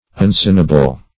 Search Result for " unsonable" : The Collaborative International Dictionary of English v.0.48: Unsonable \Un*so"na*ble\, a. [Pref. un- + L. sonabilis sounding, from sonare to sound.]